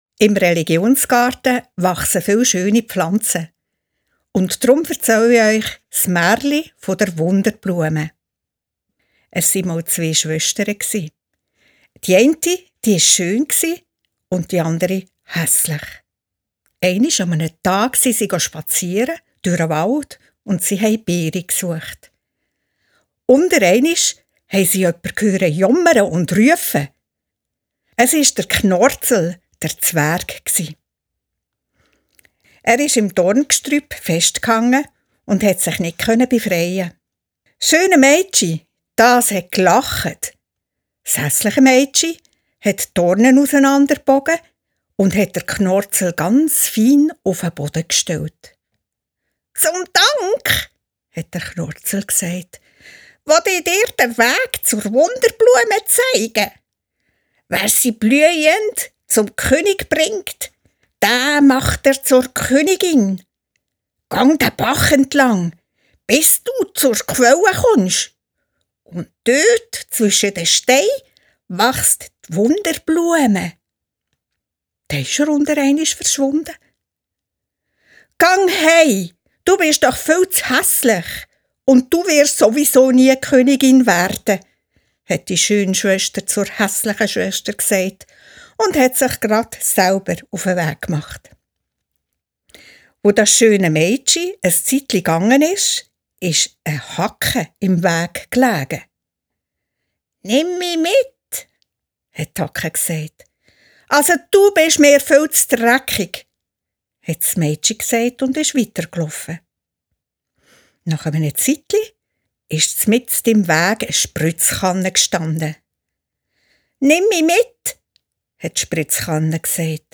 gelesen von